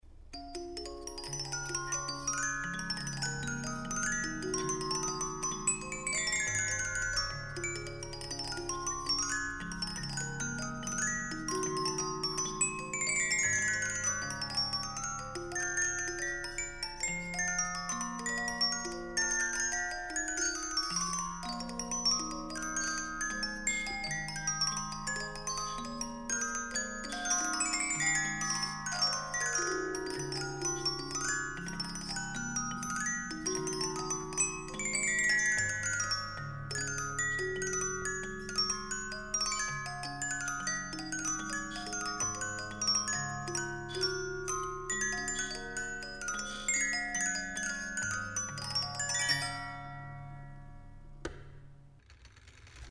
carillon 1800